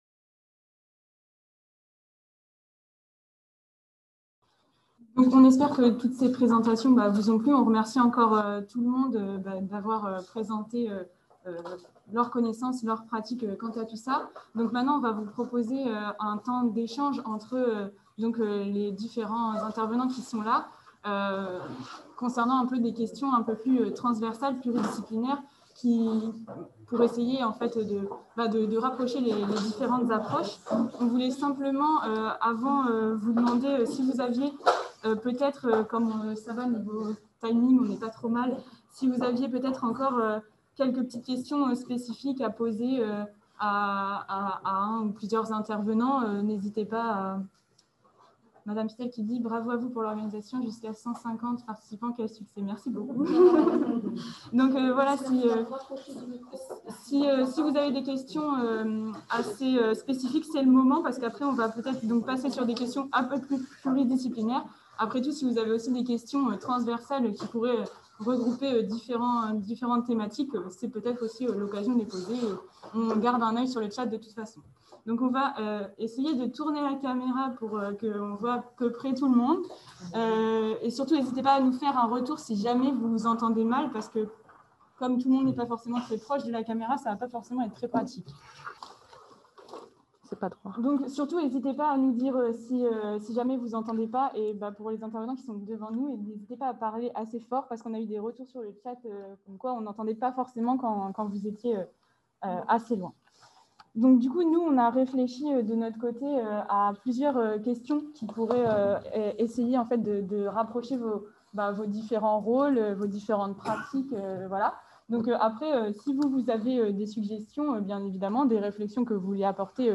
2- Le cancer chez l'enfant - Table ronde - Webinaire Master Psychologie 2021 | Canal U